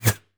bullet_flyby_fast_09.wav